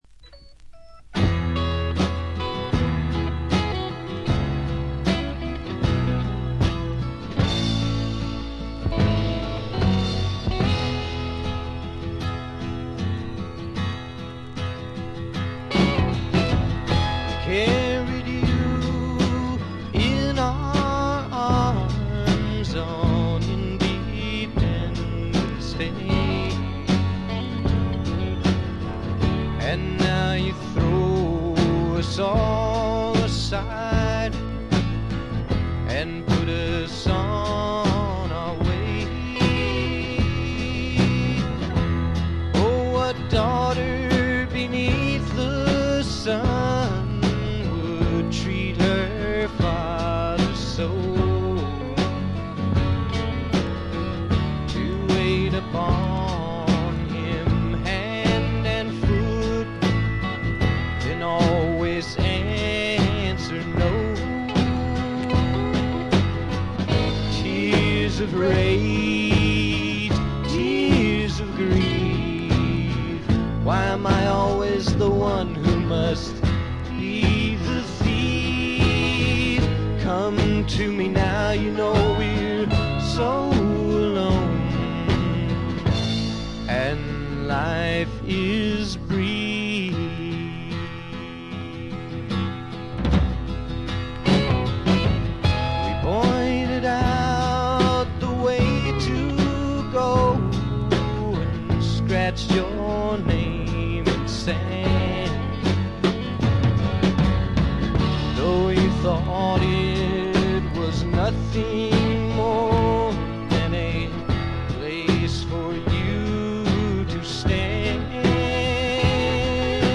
これ以外は軽微なバックグラウンドノイズにチリプチ少々。
試聴曲は現品からの取り込み音源です。
Recorded at The Village Recorder